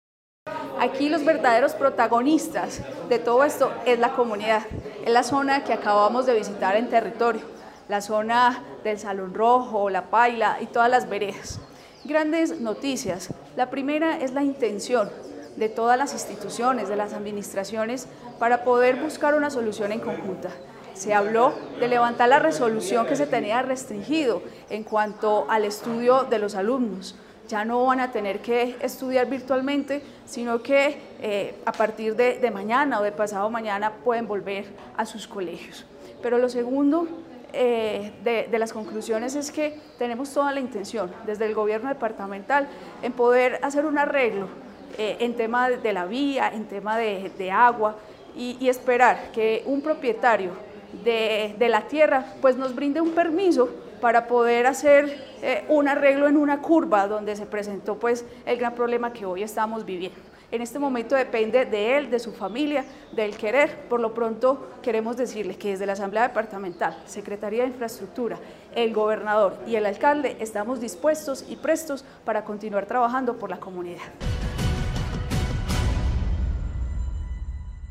Viviana Zuluaga Cardona, diputada de Caldas.
Viviana-Zuluaga-diputada-de-Caldas.mp3